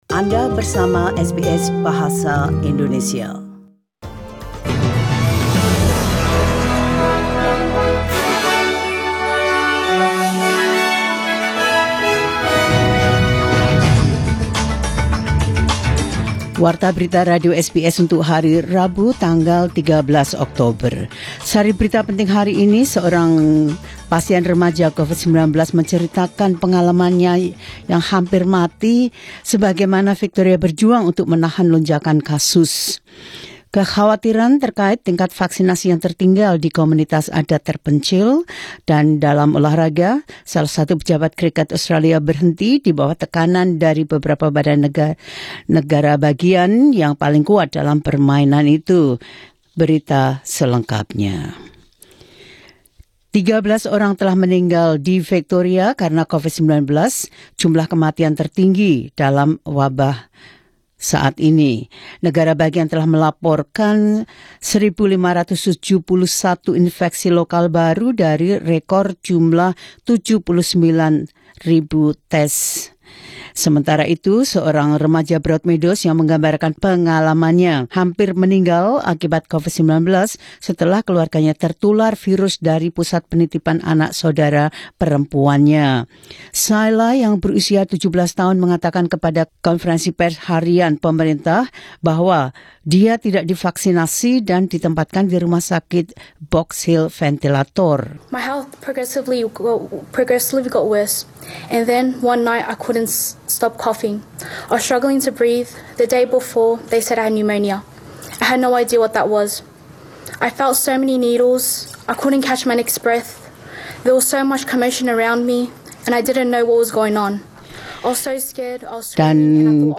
Warta Berita Radio SBS Program Bahasa Indonesia – 13 Okt 2021
Warta Berita Radio SBS Program Bahasa Indonesia – 13 Okt 2021.